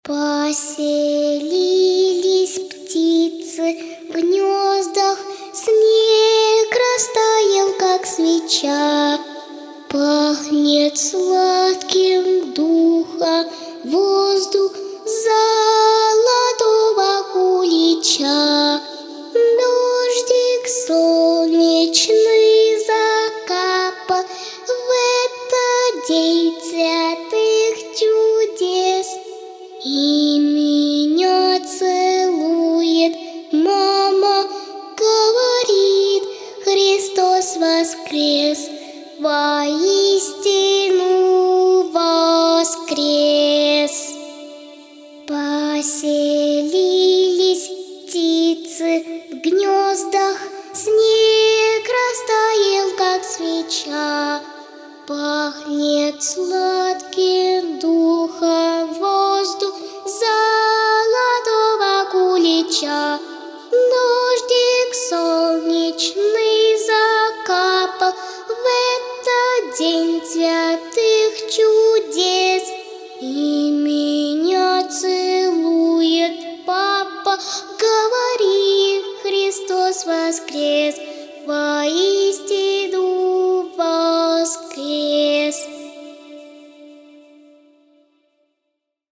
• Категория: Детские песни
🎶 Детские песни / Песни на праздник / Пасхальные песни 🥚